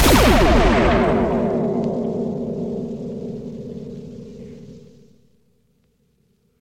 sniper.wav